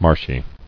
[marsh·y]